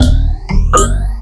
DUBLOOP 06-R.wav